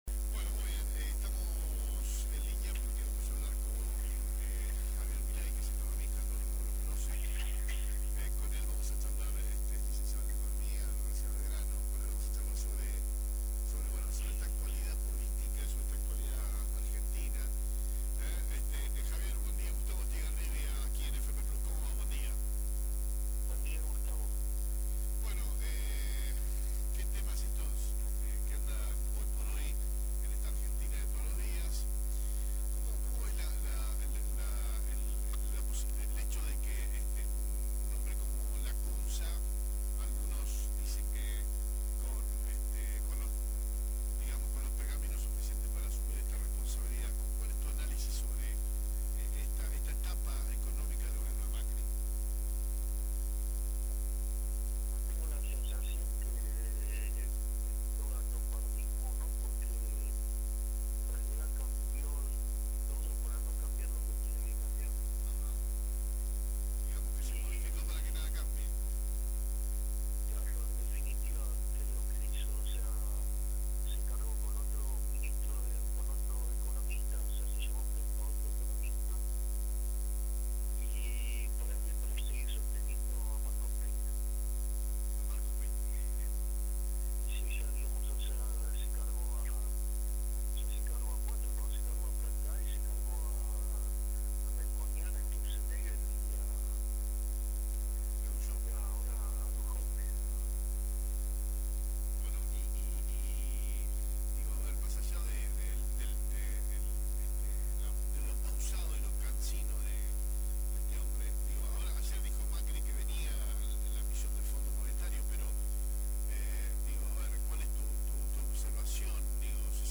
primera-mañana-telefono23-8-19.mp3